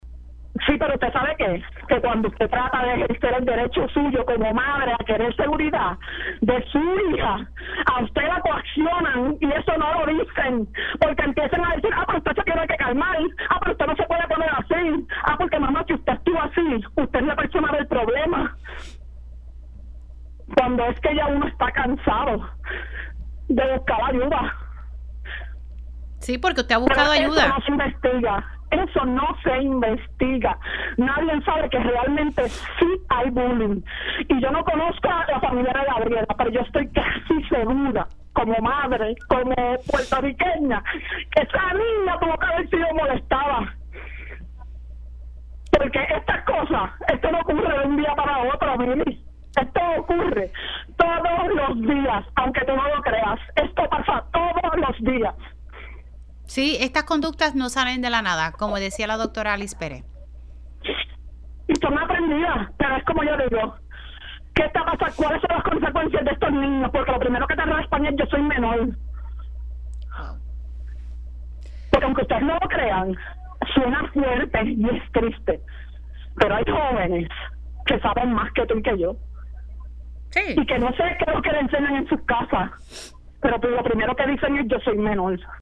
Una madre, que decidió hablar en condición de anonimato en Pega’os en la Mañana, afirmó que en ambas escuelas de nivel intermedio en las que ha estado su hija, de 12 años, ha enfrentado acoso por parte de sus compañeros y compañeras, sin que ninguna autoridad tome acción.